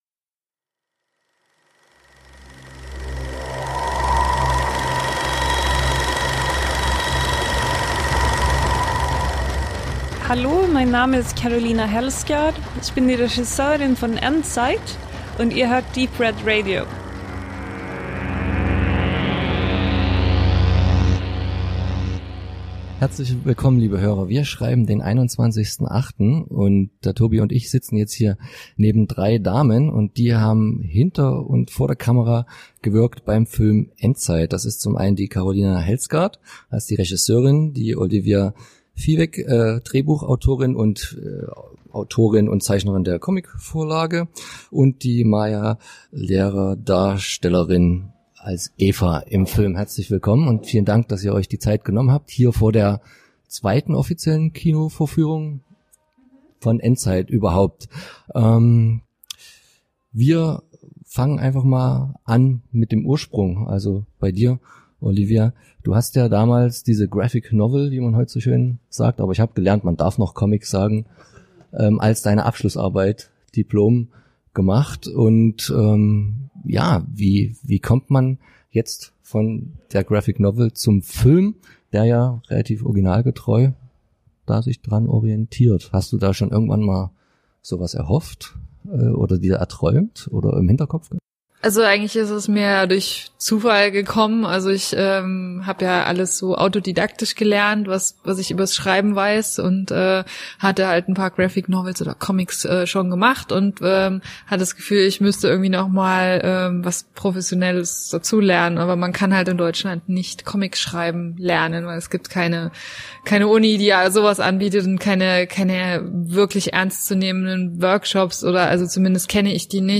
Während also die Zuschauer den Film genießen konnten, holten wir uns die anwesenden Damen von Cast und Crew vors Mikrofon.